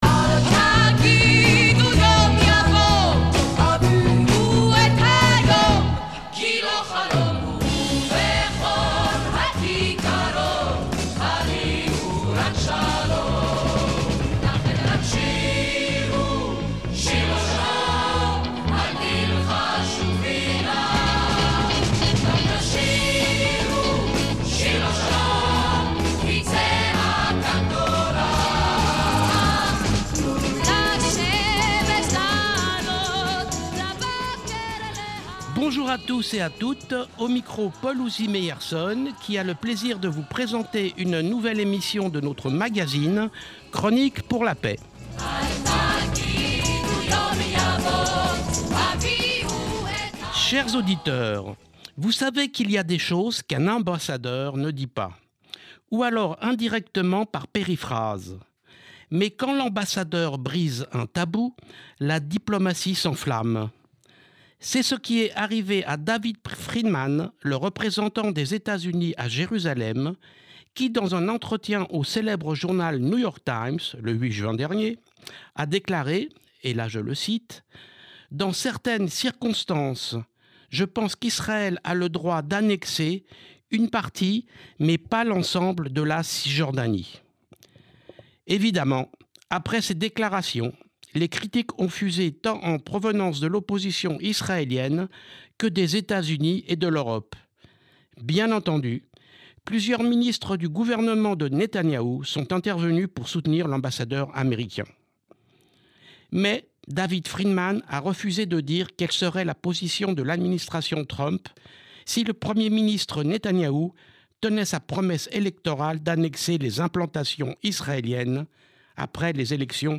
Elie Barnavi, ancien ambassadeur d’Israël en France, actuellement directeur scientifique du musée de l’Europe à Bruxelles, répond aux questions